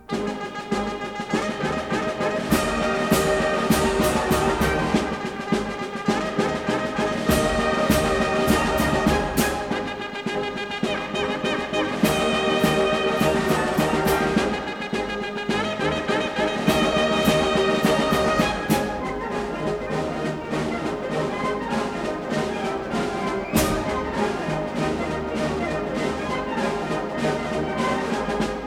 A 1957 stereo recording